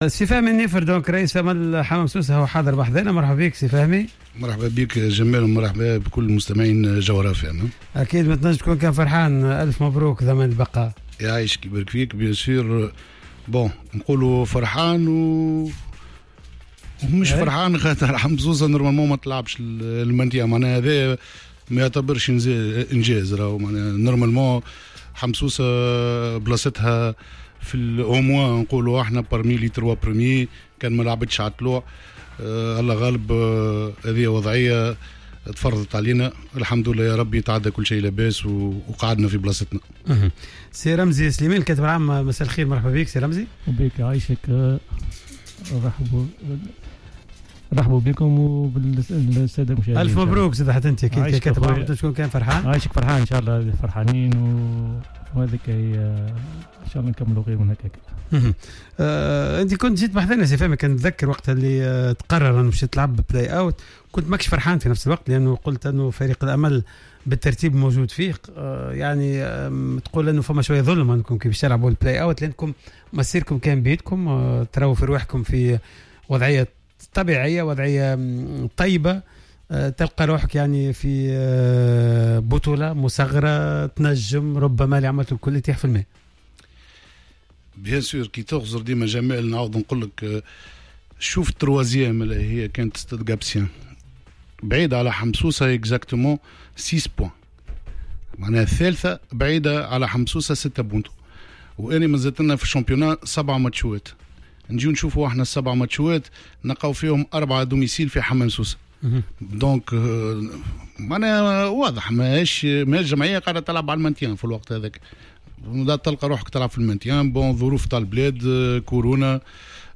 في برنامج راديو سبور